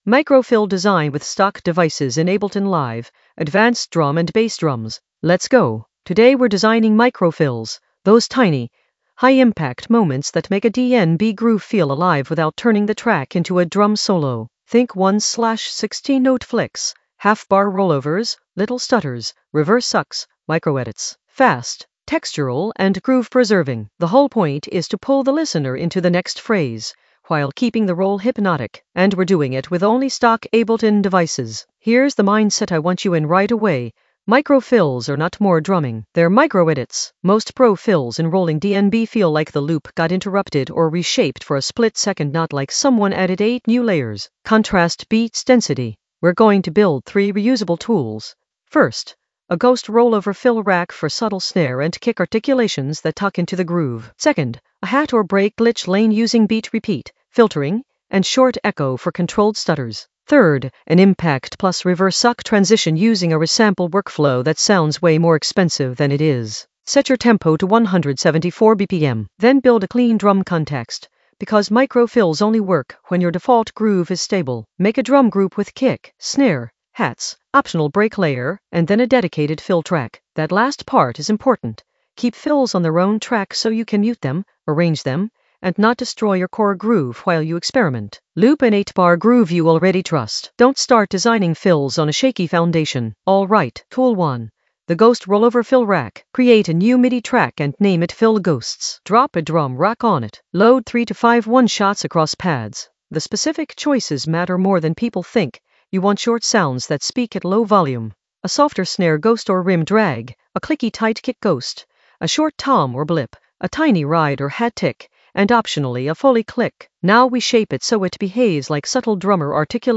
Narrated lesson audio
The voice track includes the tutorial plus extra teacher commentary.
An AI-generated advanced Ableton lesson focused on Micro-fill design with stock devices in the Drums area of drum and bass production.